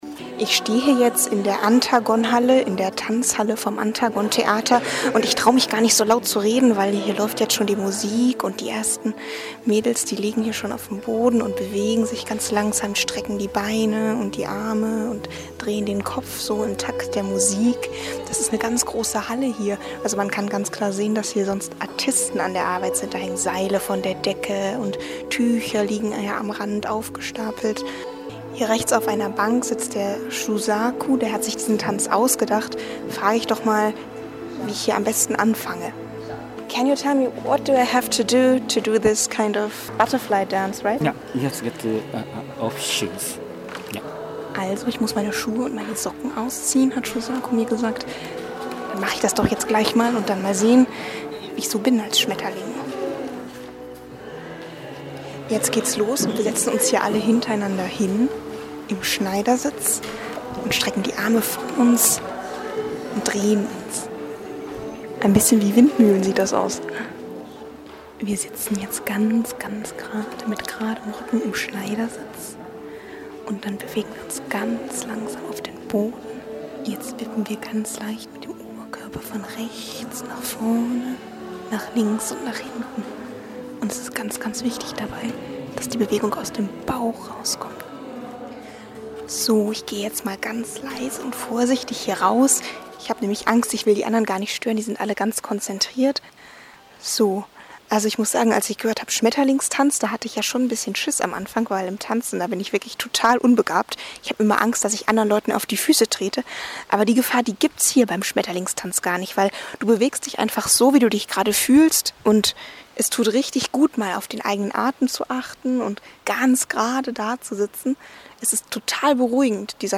Schmetterlingstanz_Reportage.mp3